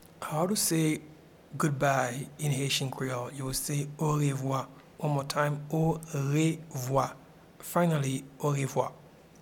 Pronunciation and Transcript:
Goodbye-in-Haitian-Creole-Orevwa.mp3